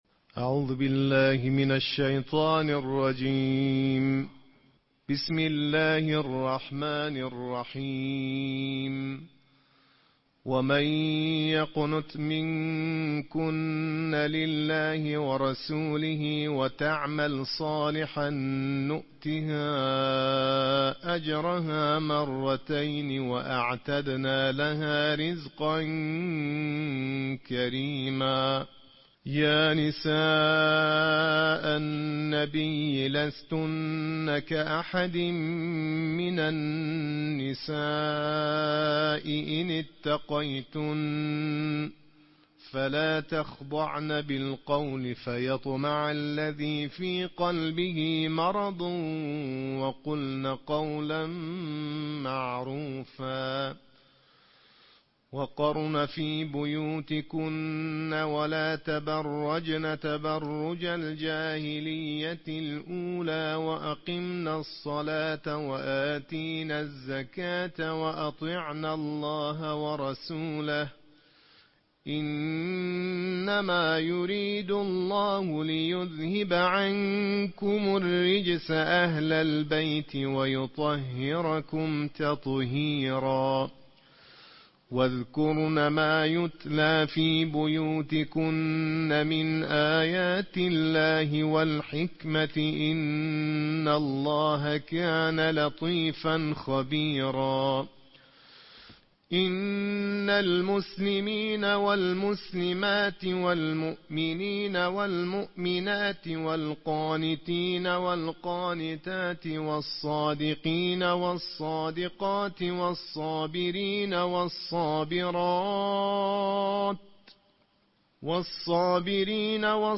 Pembacaan Tartil Juz 22 oleh Para Qari Internasional
tratil juz 22